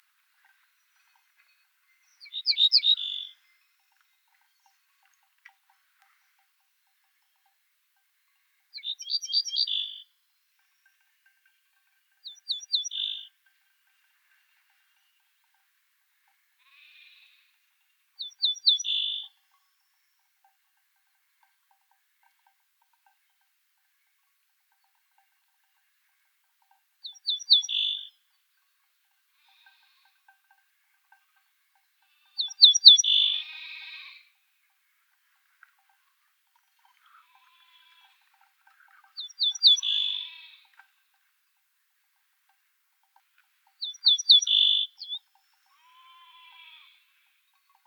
Emberiza hortulana - Ortolan bunting - Ortolano